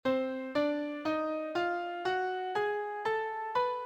A symmetrical melody: C D D# F F# G# A B stays the same when translated two steps to the right and up three semitones.